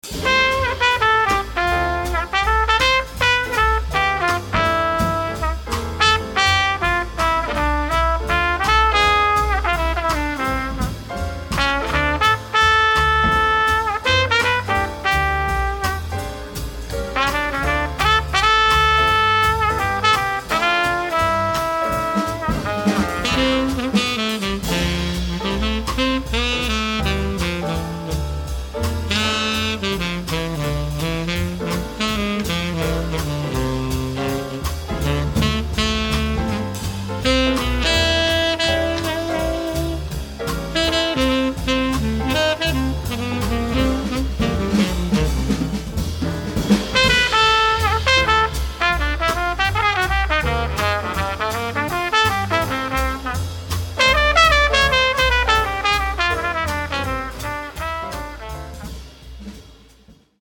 Instrumental Rat-Pack Jazz Band
(5-piece)